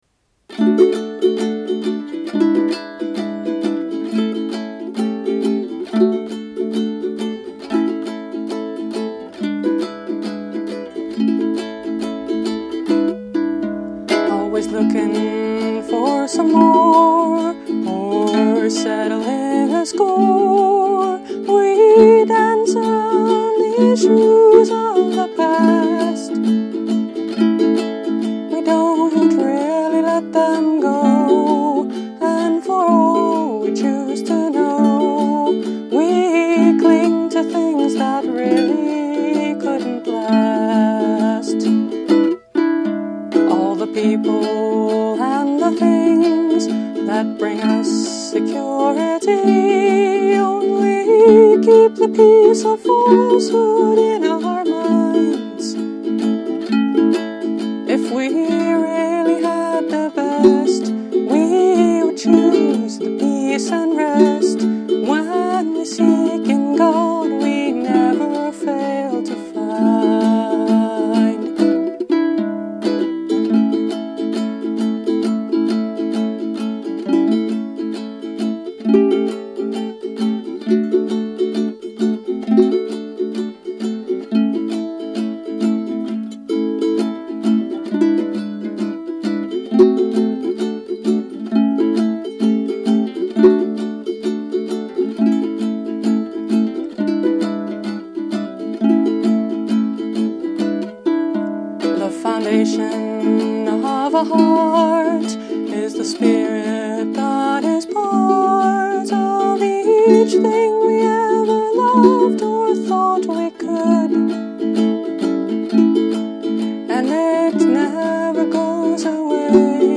Settled – Spiritual Song
Instrument: Eventide – Mahogany Concert Ukulele